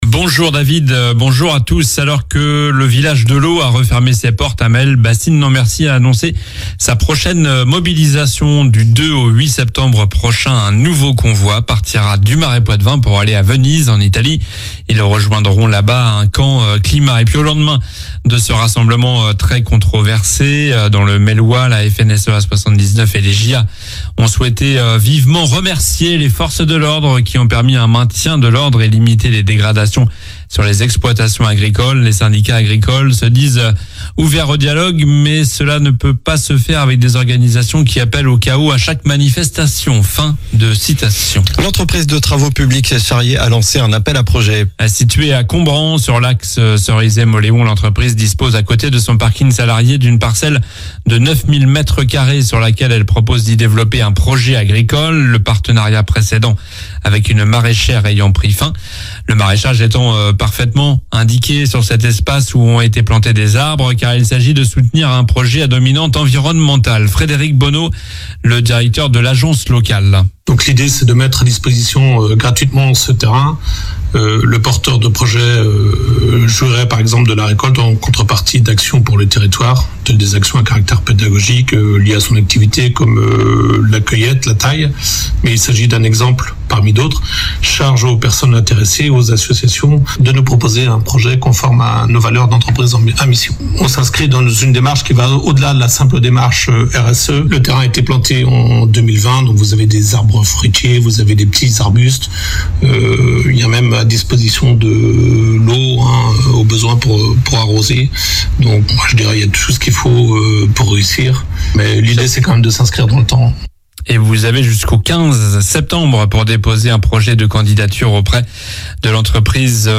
Journal du mardi 23 juillet (matin)